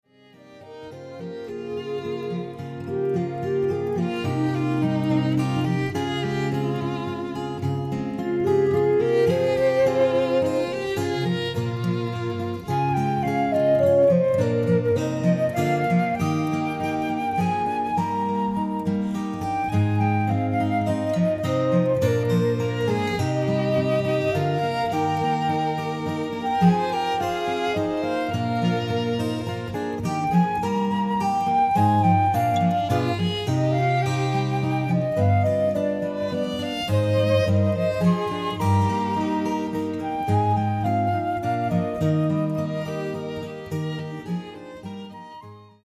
Voicing: Cantor; Assembly